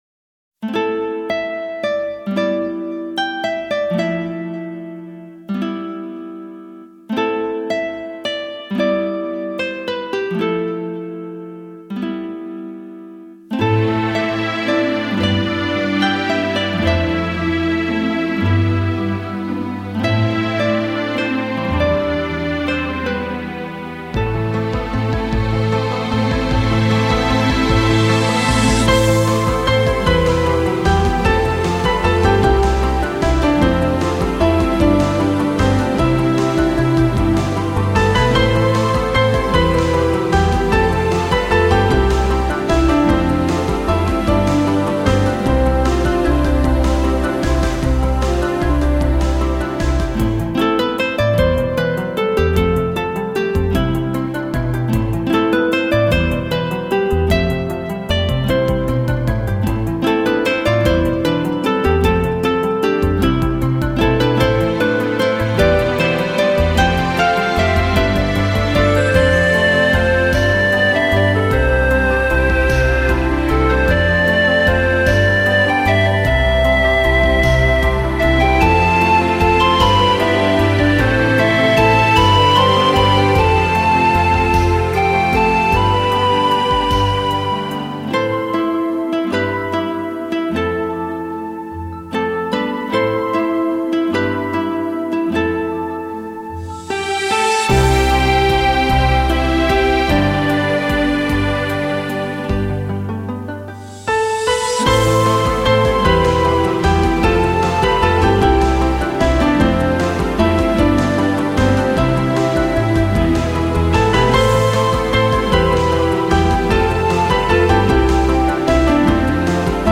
宇宙に響く透明なサウンド!
歌曲中心の構成。